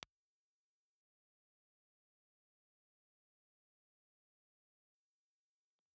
Ribbon
Nylon string guitar recorded with the Amperite RBHG ribbon microphone.